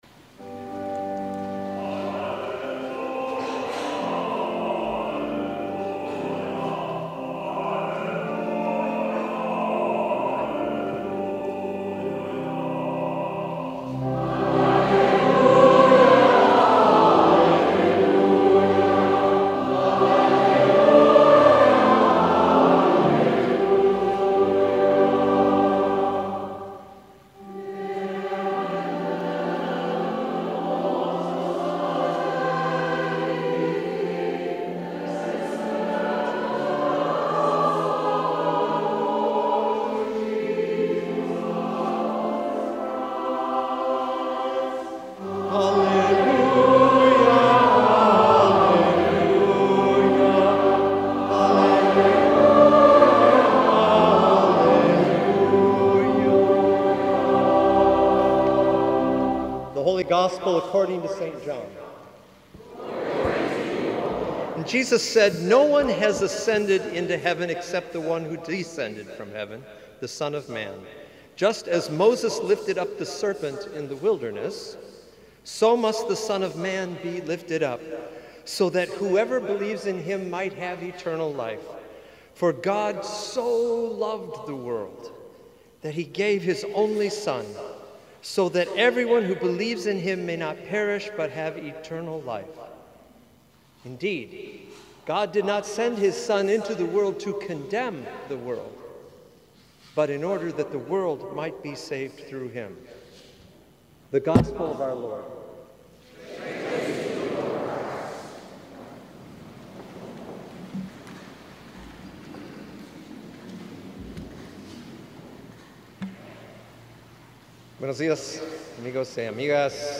Sermon from the Seventeenth Sunday After Pentecost (Holy Cross)